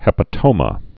(hĕpə-tōmə)